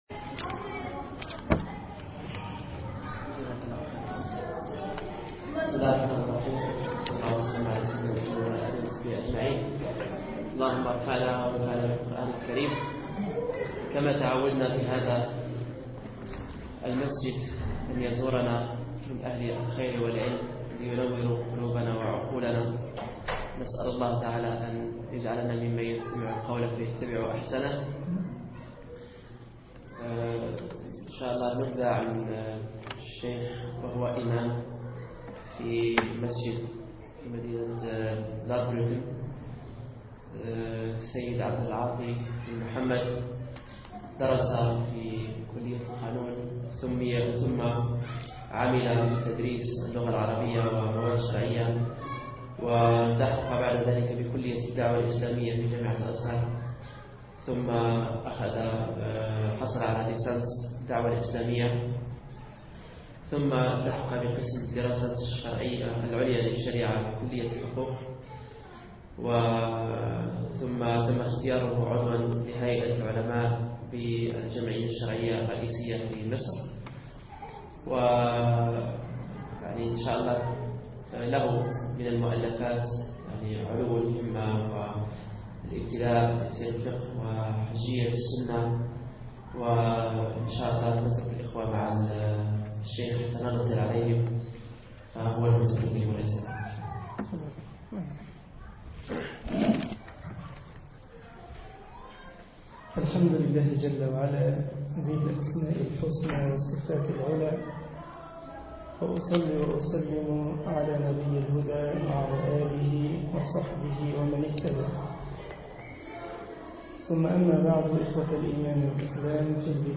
درس عقب خطبة الجمعة
مدينة بوخوم ــ ألمانيا